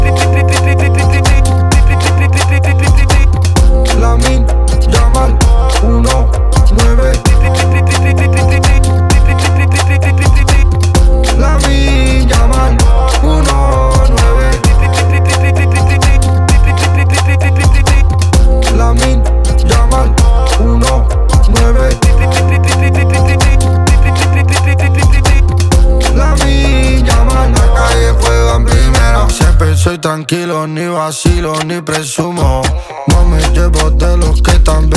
Скачать припев
2025-07-04 Жанр: Поп музыка Длительность